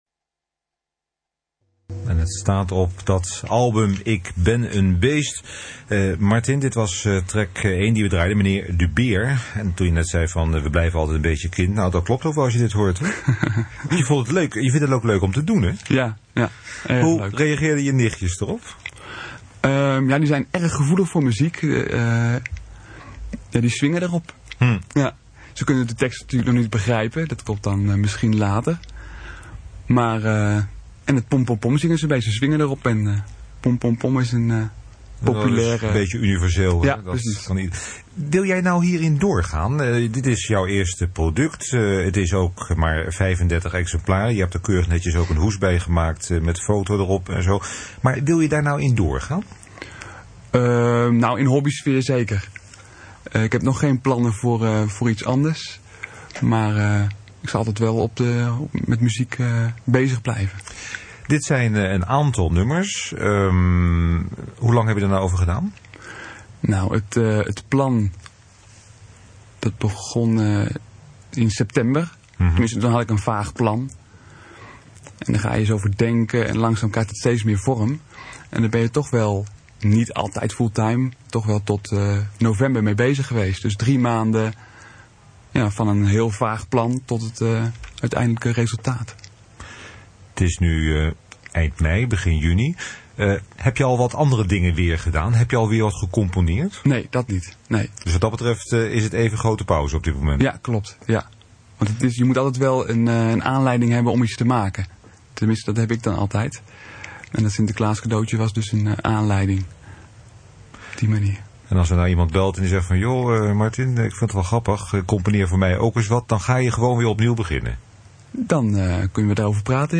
Interview Radio West - deel 2 (mp3): 1.238 kB / 5 min 16 sec
• De radio-interviews op deze pagina zijn mono met een bitsnelheid van 32 kb/s.